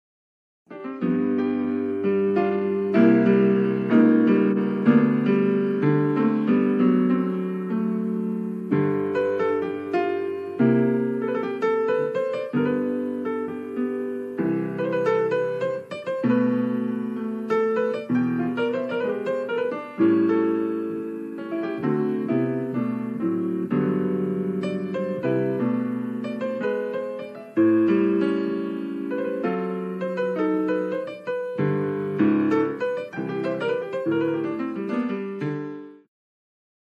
en versió jazz